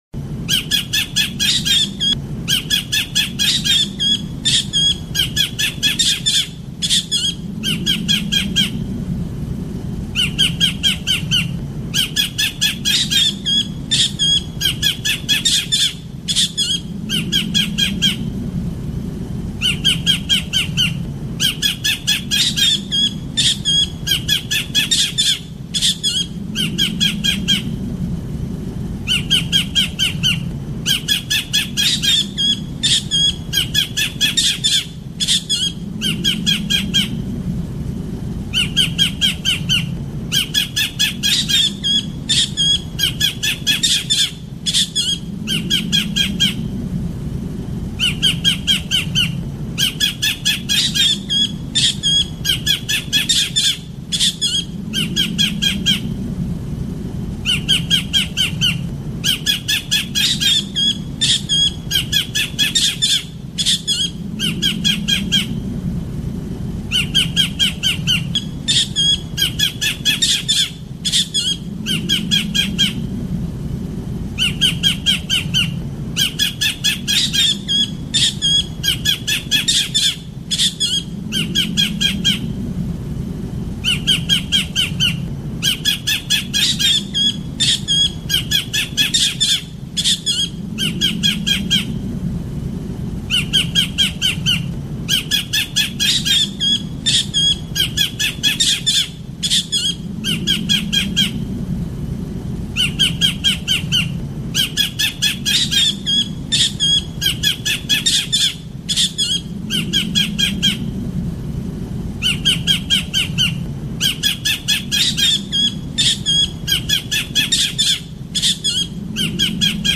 Suara burung Tengkek Udang ini jitu banget, ngekeknya panjang dan tajam, cocok banget buat kicau mania yang lagi cari masteran berkualitas.
Suara Tengkek Udang
Suara ngekeknya yang khas ini pasti bikin burung kamu jadi lebih gacor.
suara-burung-tengkek-udang-id-www_tiengdong_com.mp3